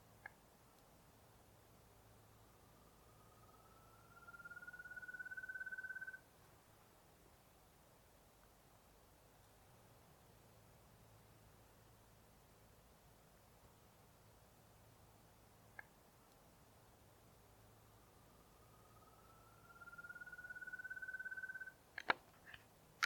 Rufous-tailed Antthrush (Chamaeza ruficauda)
Life Stage: Adult
Location or protected area: Parque Provincial Caá Yarí
Condition: Wild
Certainty: Observed, Recorded vocal